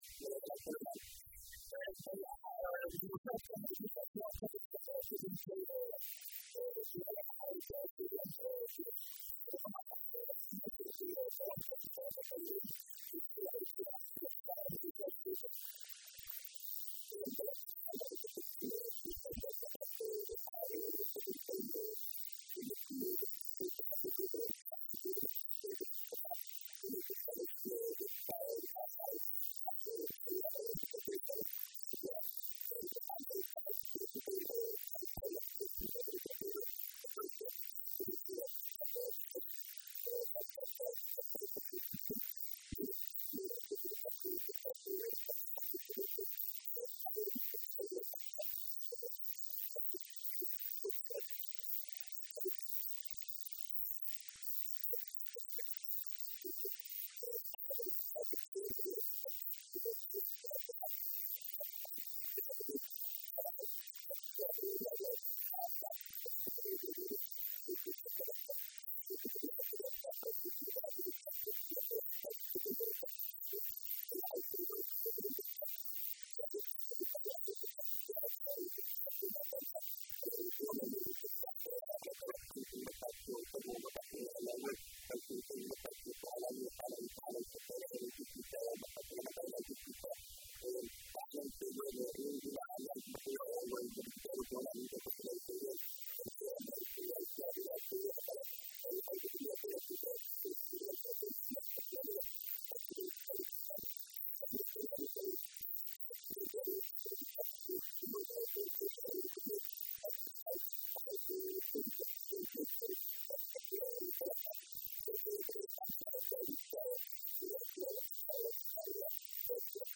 Codka Wariye